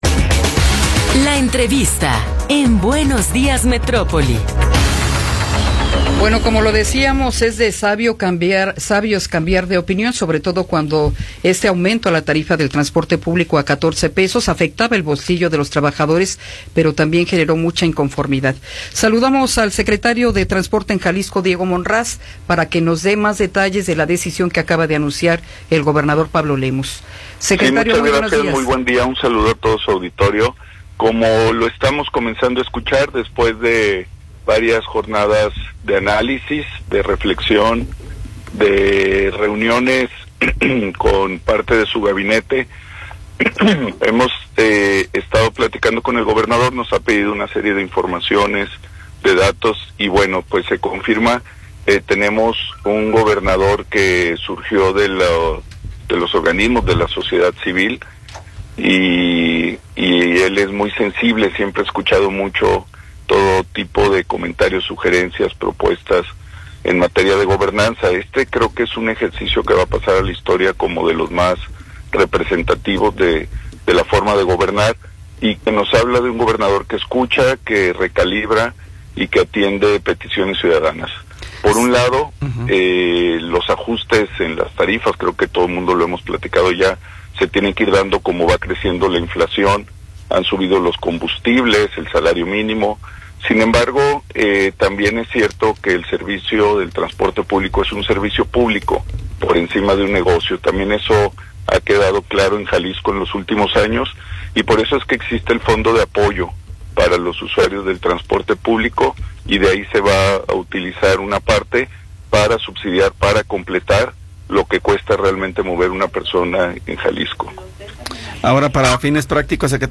Entrevista con Diego Monraz Villaseñor
Diego Monraz Villaseñor, secretario de Transporte de Jalisco, nos habla sobre los seis meses de operación del Peribús.